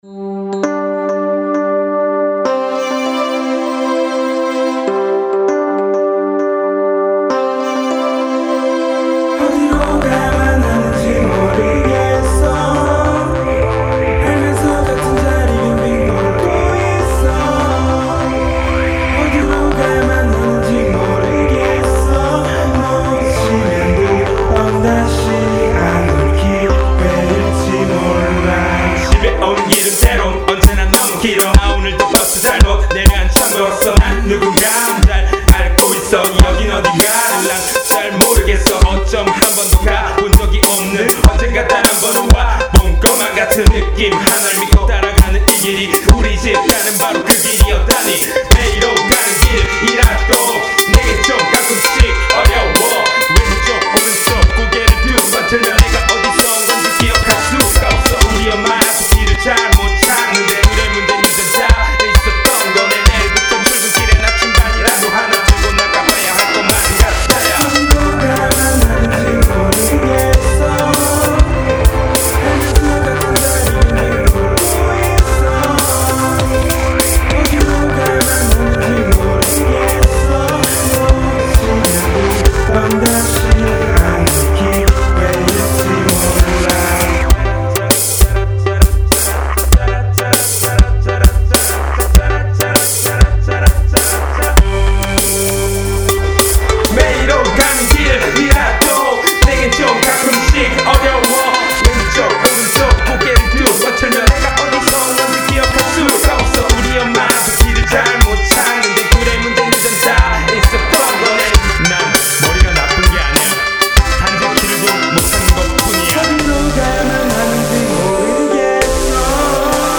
(뭐 그래도 전부 찍은 노래라 작법만 보면 리드머에 올리기 적절하다고 생각합니다.)